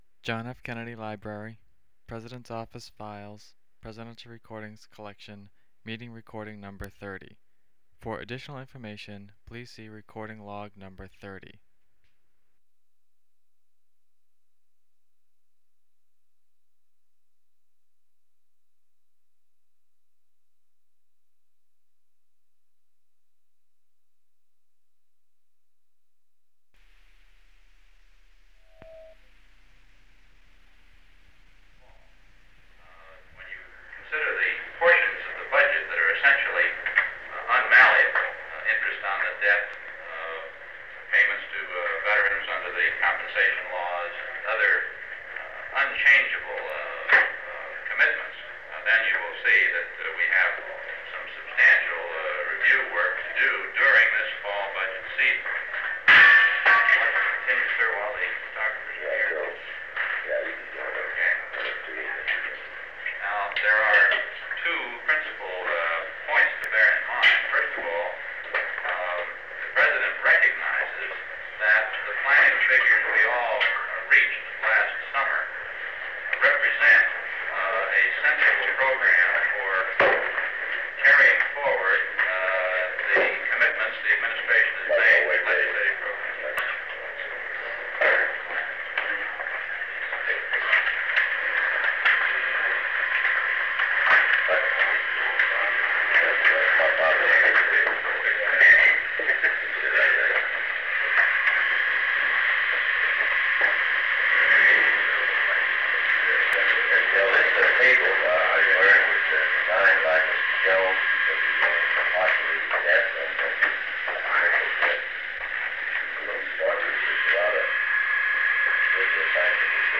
Cabinet Meeting on the Federal Budget for Fiscal Year 1964
Secret White House Tapes | John F. Kennedy Presidency Cabinet Meeting on the Federal Budget for Fiscal Year 1964 Rewind 10 seconds Play/Pause Fast-forward 10 seconds 0:00 Download audio Previous Meetings: Tape 121/A57.